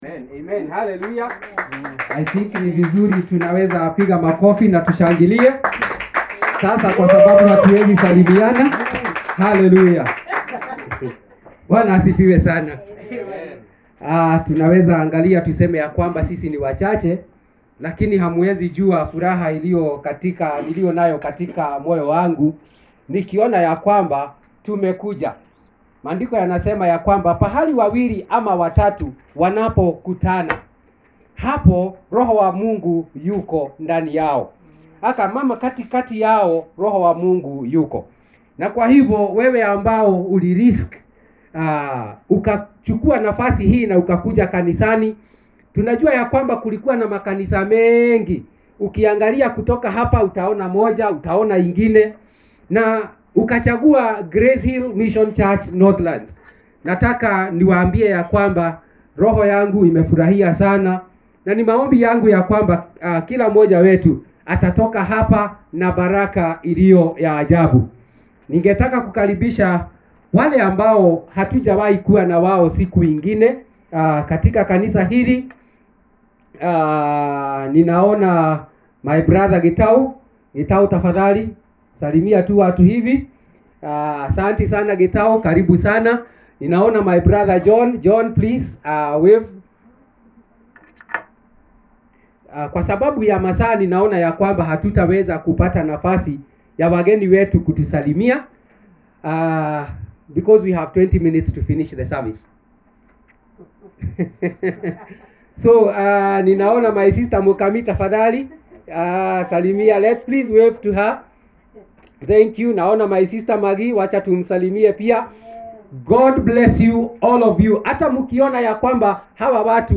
19th July 2020 Sermon